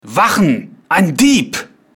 Deutsche Sprecher (m)
mittellalter, duchsetzungsf�higer Dunmer